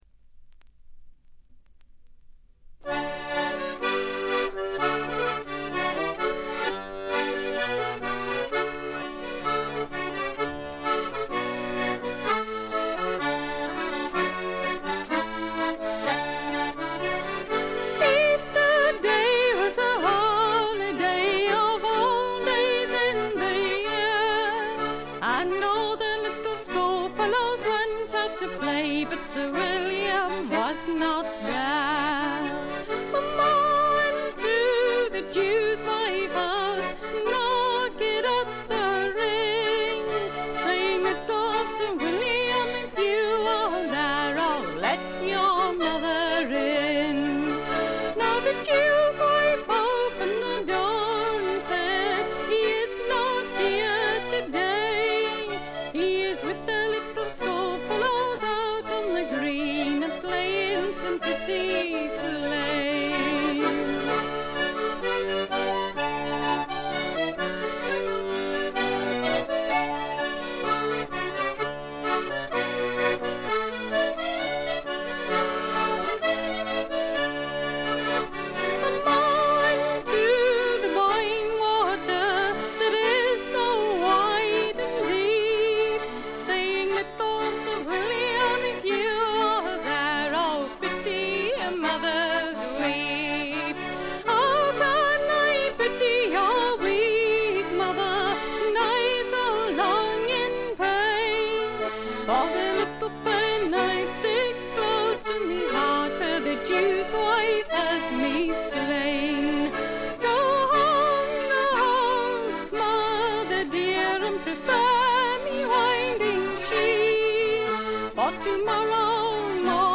部分試聴ですが、静音部で軽微なチリプチが少し、散発的なプツ音が少し。
本作もトラッド・アルバムとして素晴らしい出来栄えです。
試聴曲は現品からの取り込み音源です。
Recorded At - Riverside Studios, London